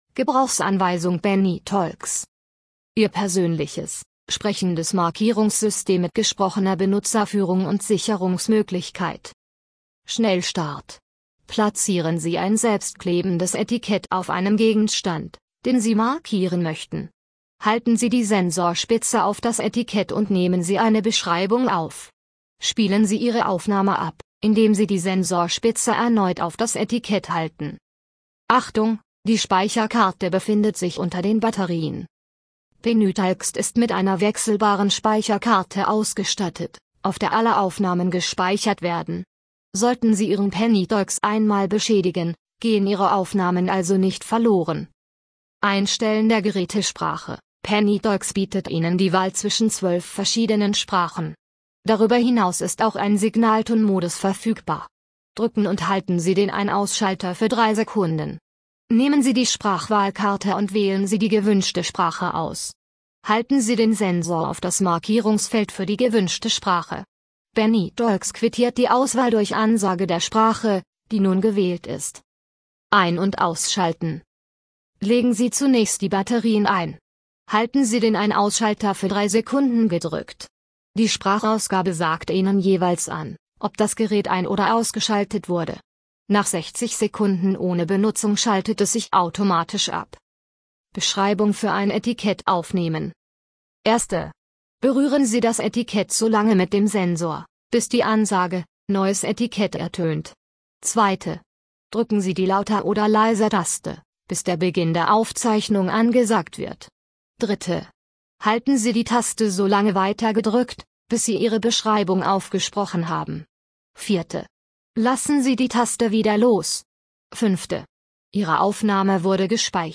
Bedienungsanleitung PennyTalks in MP3-Format (Audio)